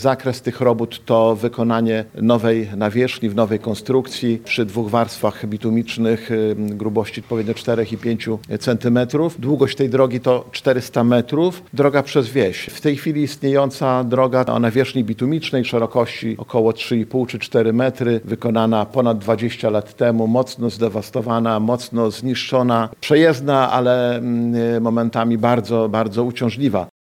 O szczegółach inwestycji mówił starosta łomżyński Lech Szabłowski.